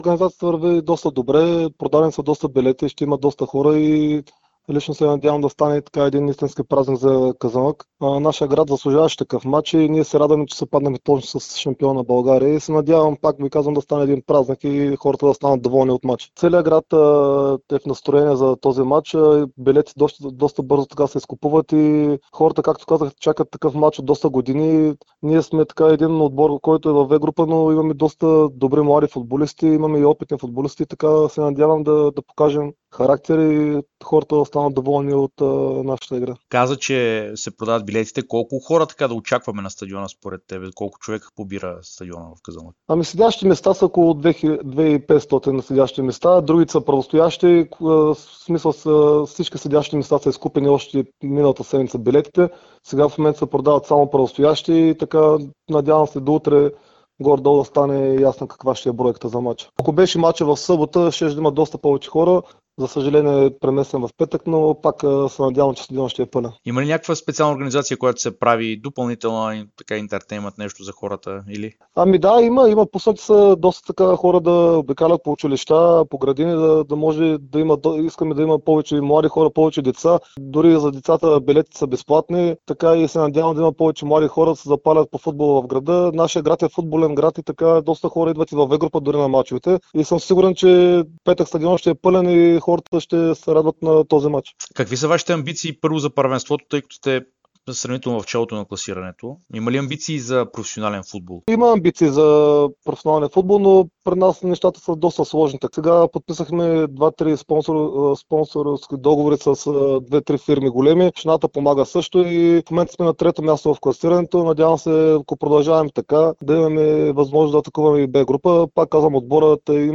даде специално интервю пред Дарик и dsport преди двубоя на "розите" срещу Лудогорец в турнира за Sesame Купа на България.